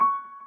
piano_last36.ogg